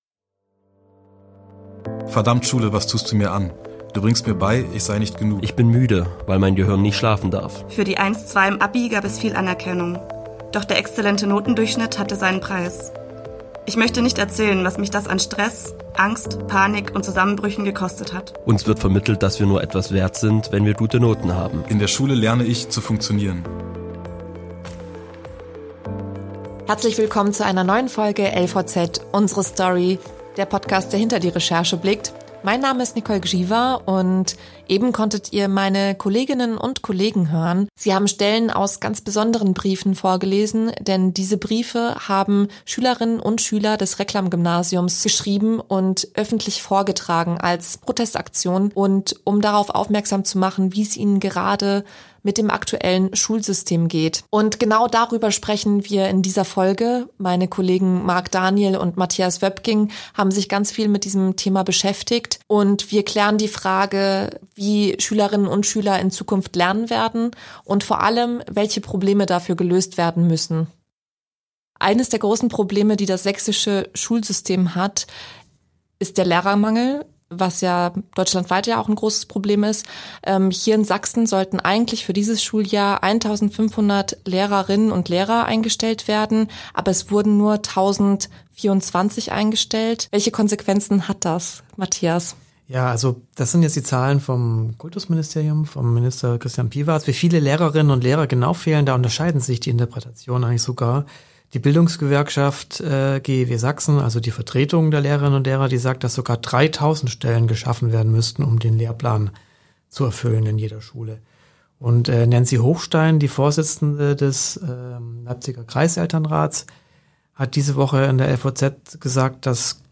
Und es wird persönlich: Die Reporter sprechen über ihre eigene Schulzeit und besprechen auch, was sie von dem Druck halten, den die Kinder heute bekommen.